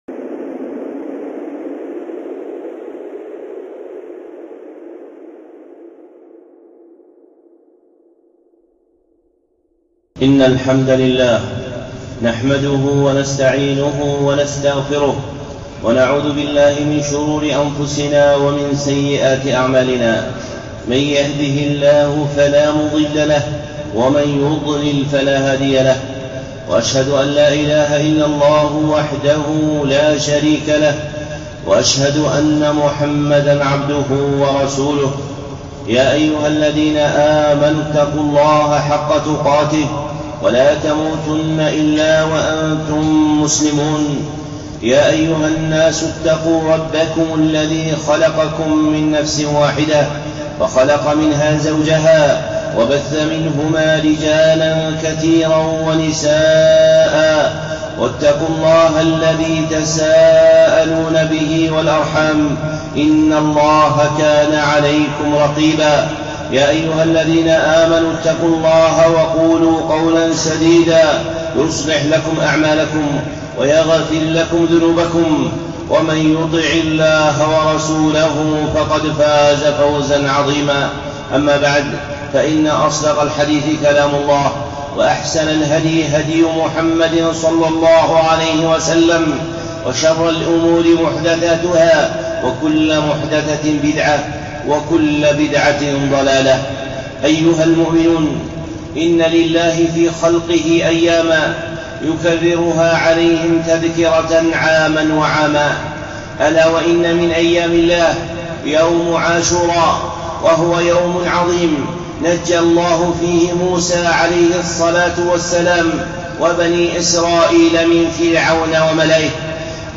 خطبة
الخطب المنبرية